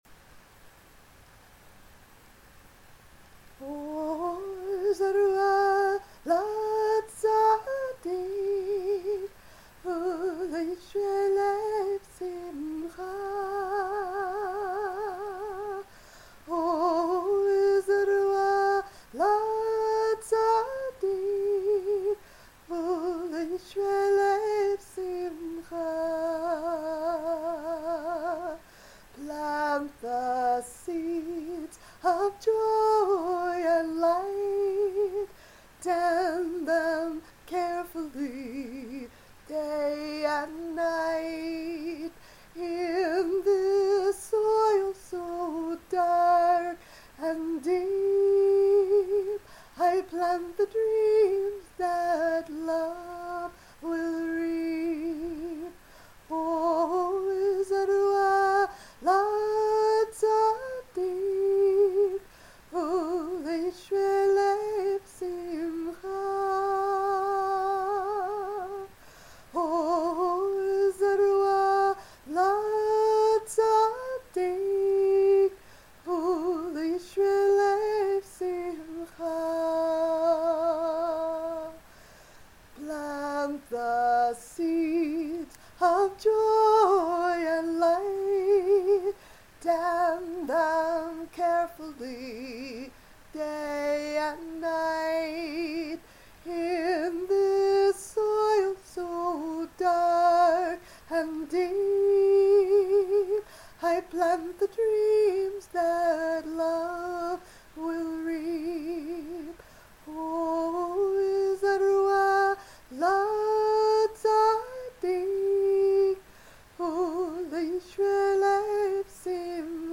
Chants, Psalms